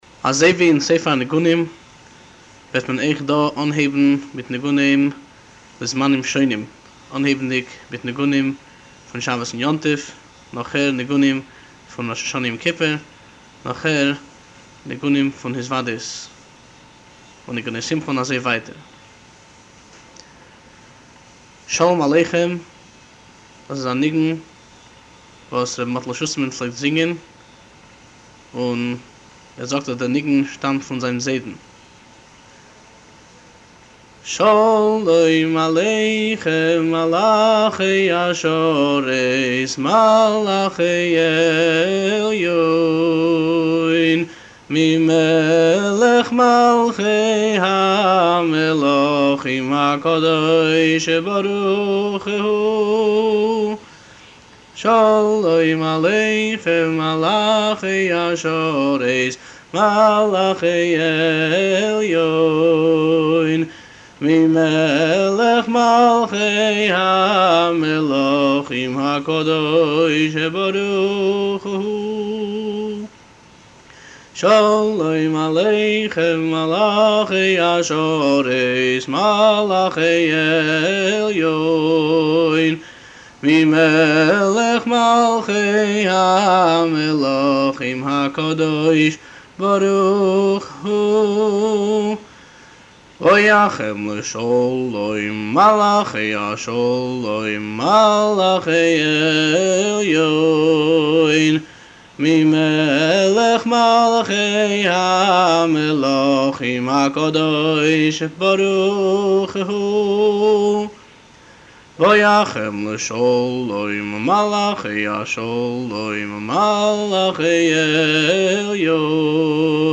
בניגון זה, 2 הבתים הראשונים בפיוט ('שלום עליכם' ו'בואכם לשלום') חוזרים על עצמם עם אותה מנגינה, ואילו ל-2 הבתים האחרונים בניגון ('ברכוני לשלום' ו'צאתכם לשלום') יש מנגינה אחרת שחוזרת על עצמה בשניהם.